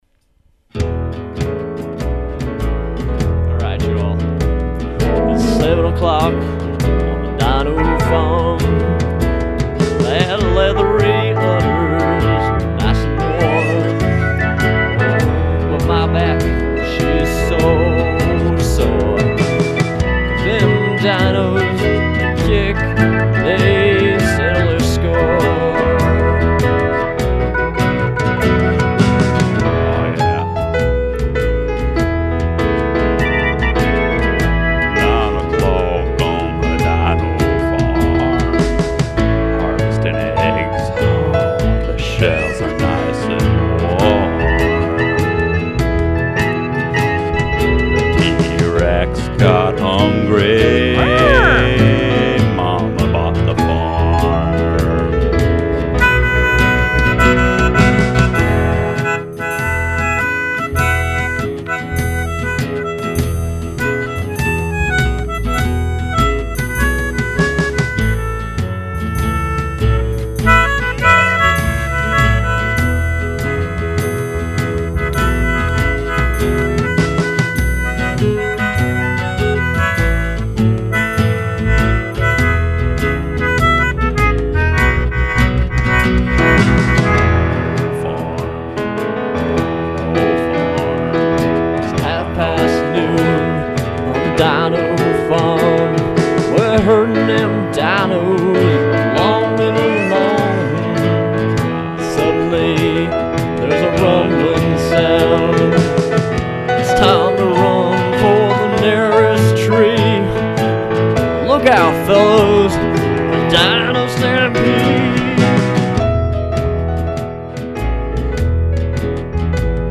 The single-take, off-the-tops-of-our-heads approach to musical composition never goes out of style.